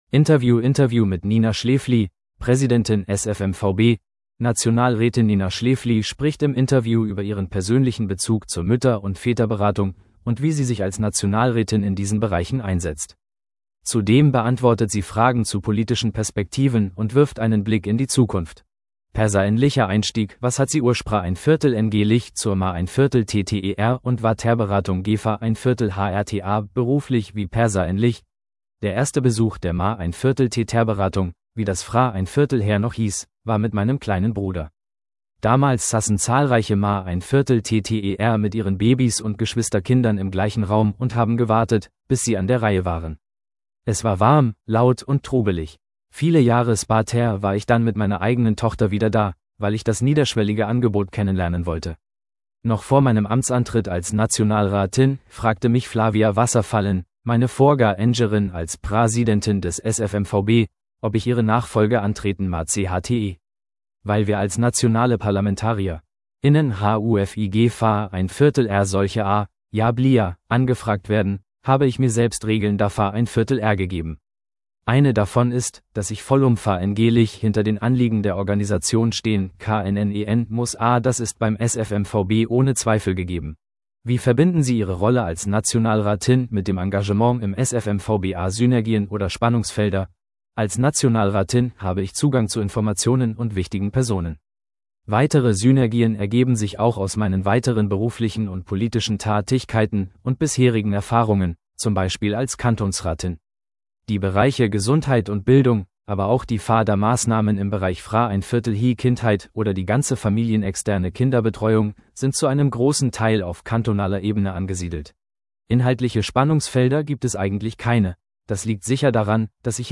Interview mit Nina Schläfli, Präsidentin SF MVB, Nationalrätin | Clic